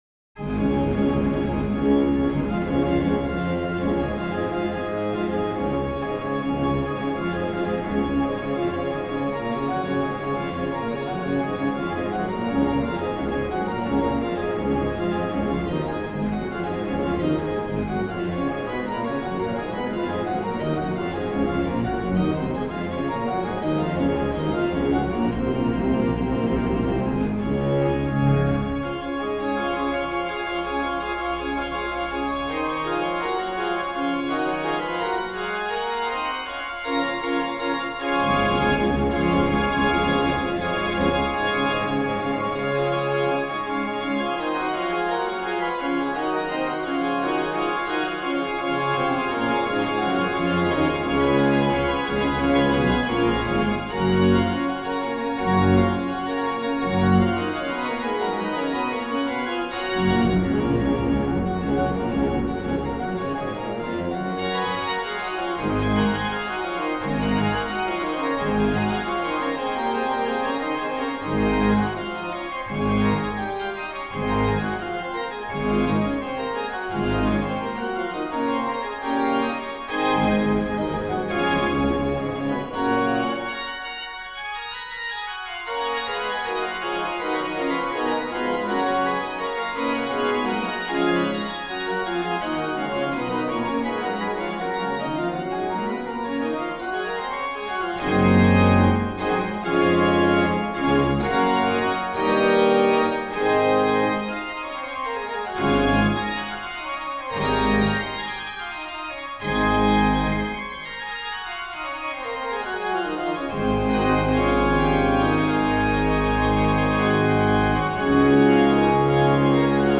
MAN: Qnt16, Pr8, Oct4, Qnt3, Oct2, POS/MAN
POS: Lged8, Pr4, Oct2, Mix
PED: Viol16, Oct8, Pos16, MAN/PED, POS/PED